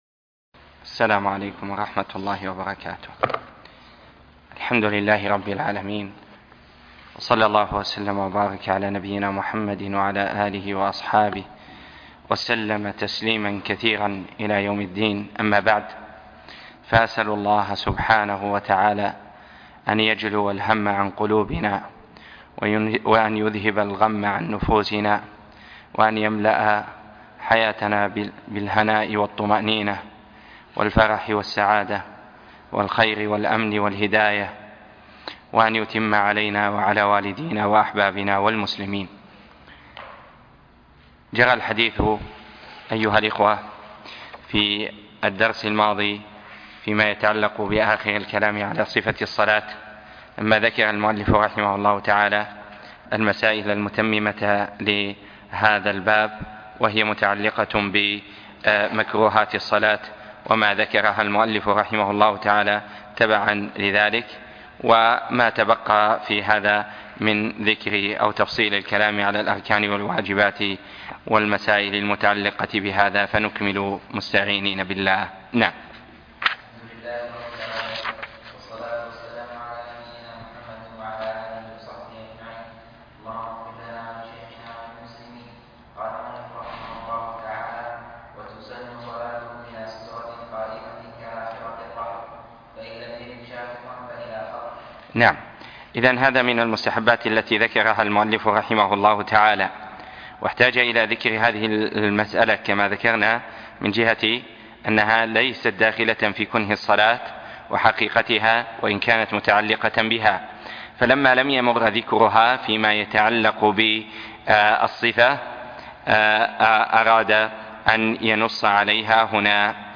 زاد المستقنع - تابع باب صفة الصلاة - الدرس (30)